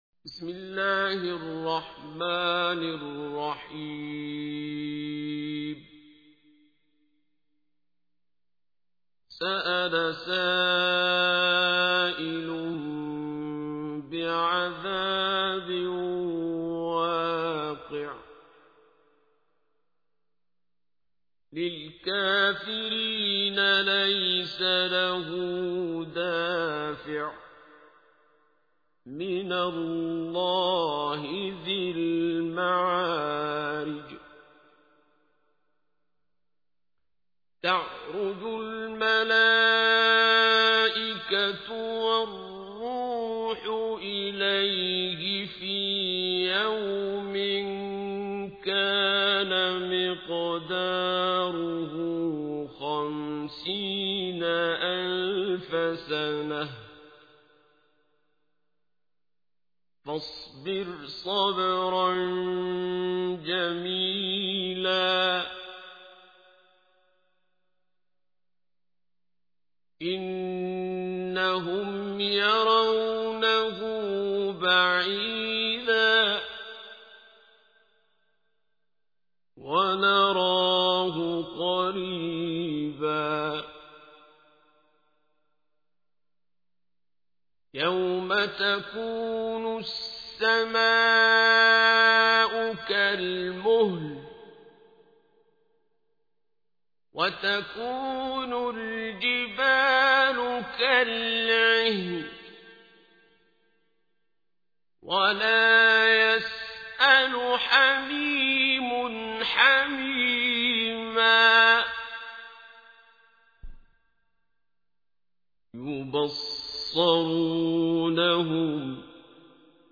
تحميل : 70. سورة المعارج / القارئ عبد الباسط عبد الصمد / القرآن الكريم / موقع يا حسين